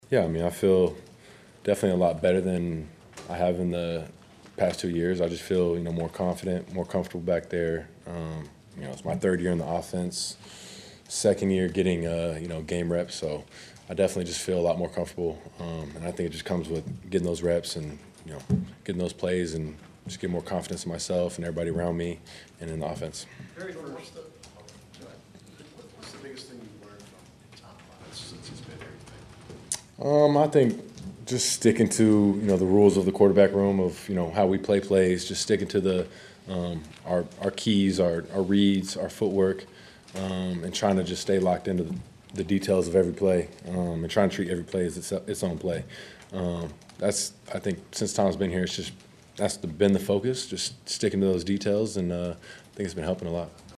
Love followed LaFleur in the media auditorium and admitted he’s in a good place with his continued development.